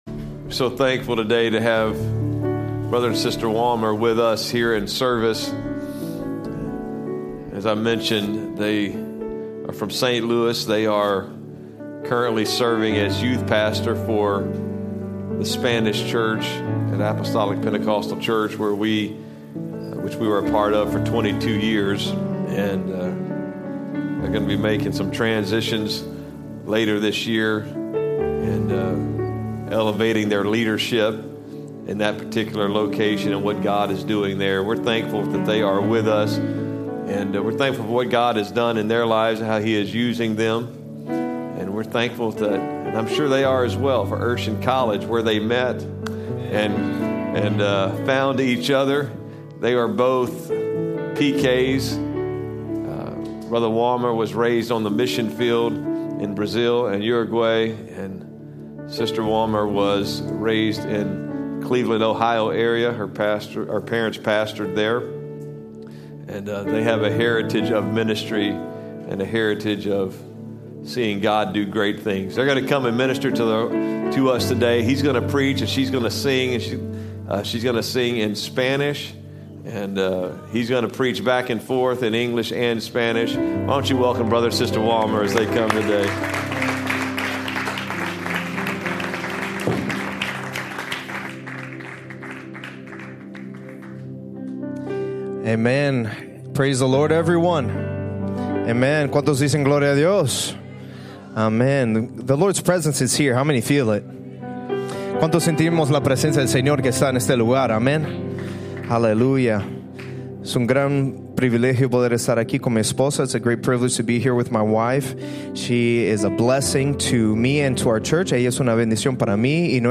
Sermons | Cross Church Kansas City
Guest Speaker